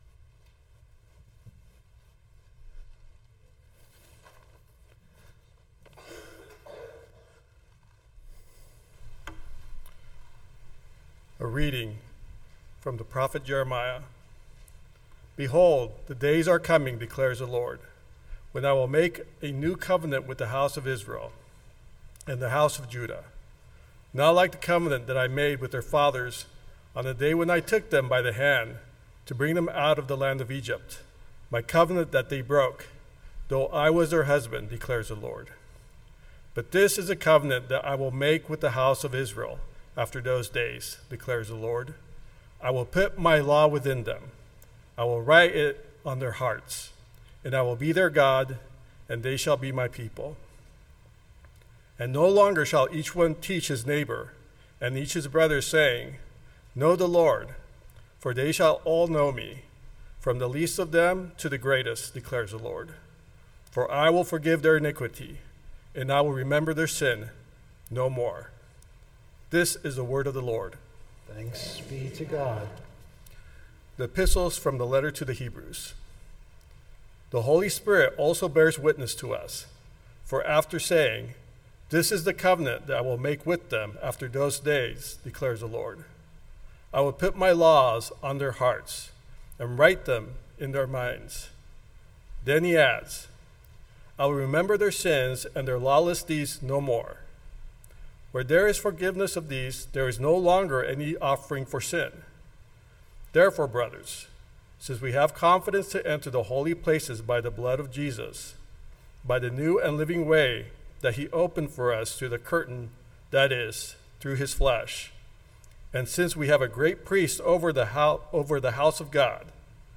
Remember (A Maundy Thursday Sermon)